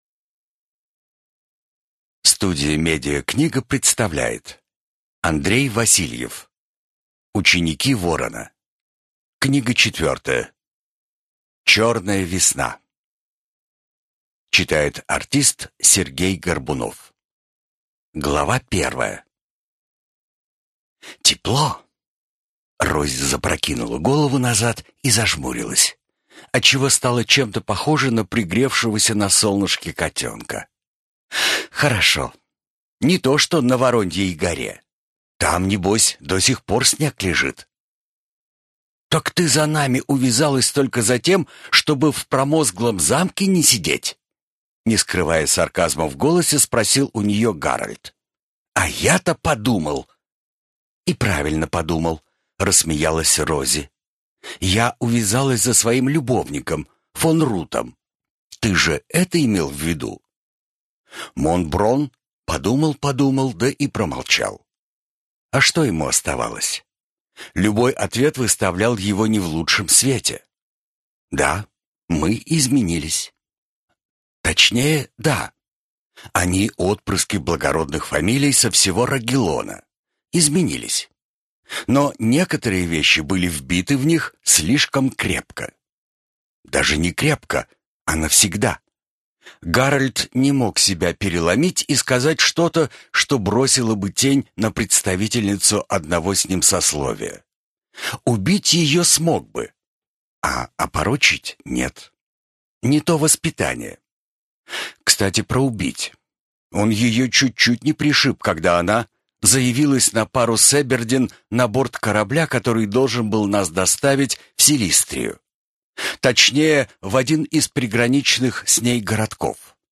Аудиокнига Черная Весна | Библиотека аудиокниг